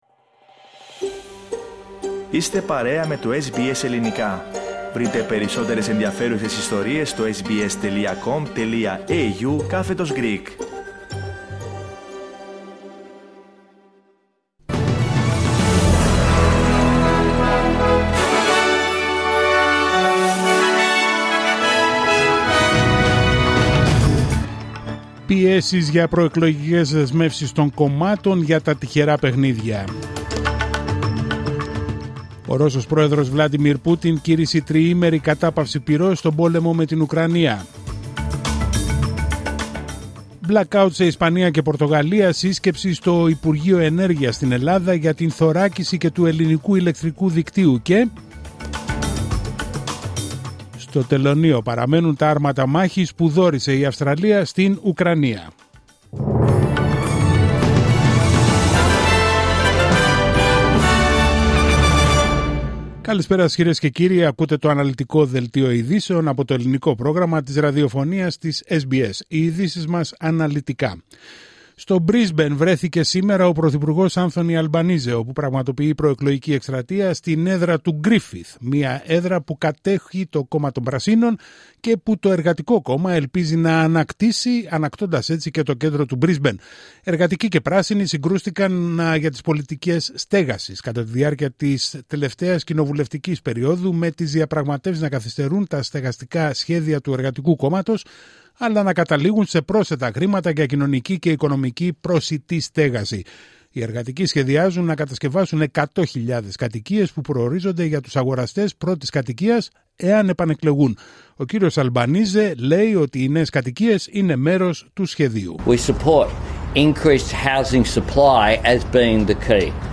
Δελτίο ειδήσεων Τρίτη 29 Απρίλιου 2025